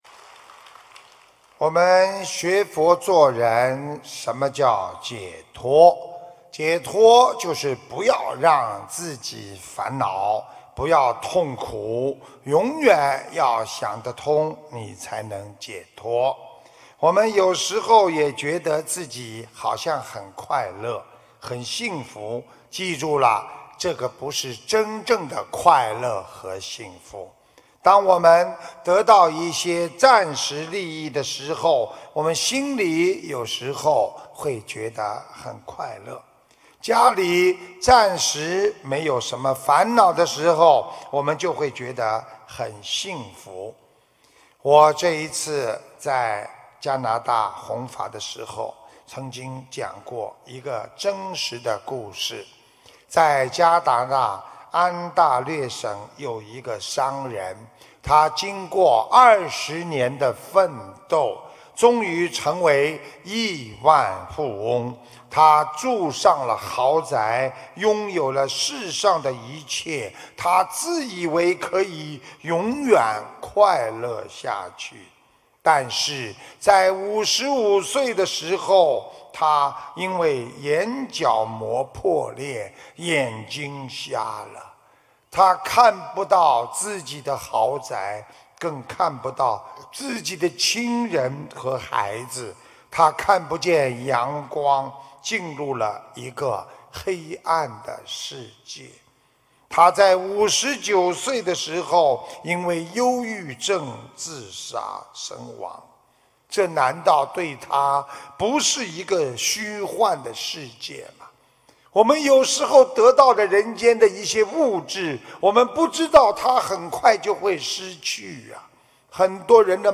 目录：☞ 2016年9月_美国_洛杉矶_开示集锦